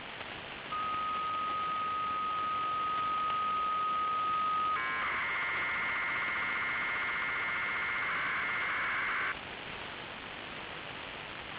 Начало » Записи » Радиоcигналы на опознание и анализ